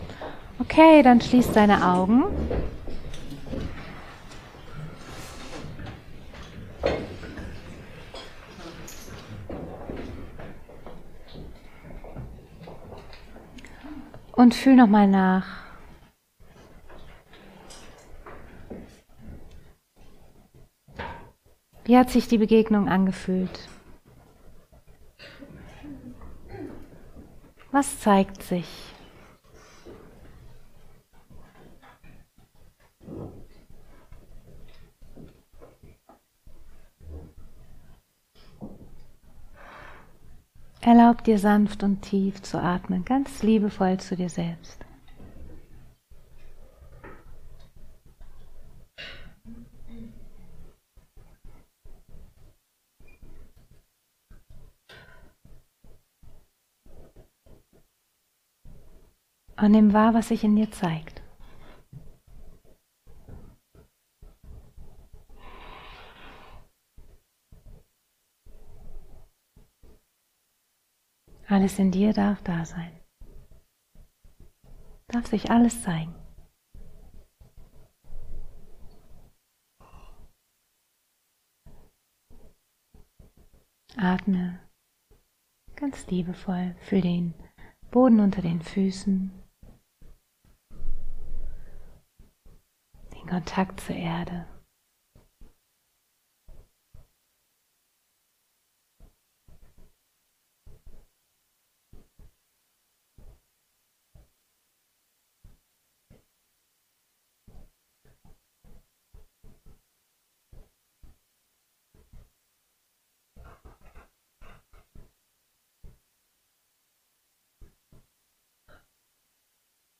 Meditationen